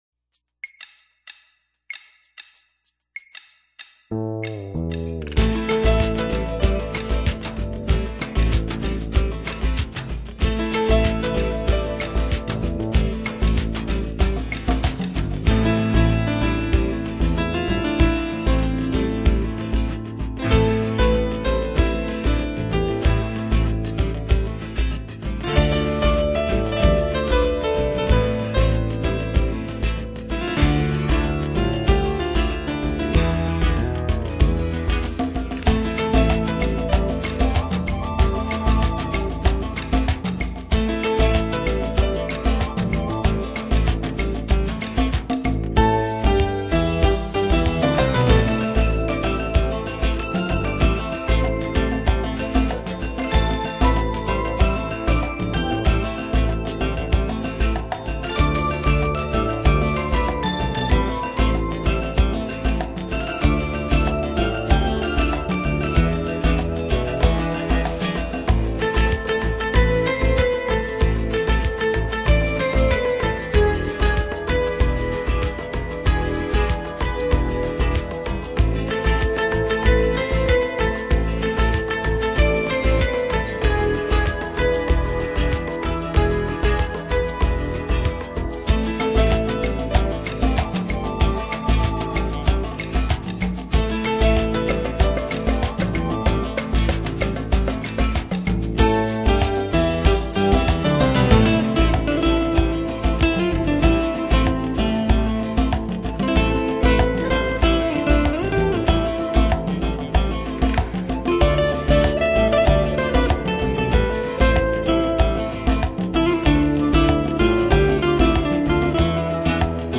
9首音乐的节奏相对是欢快的，音乐起伏并不是很大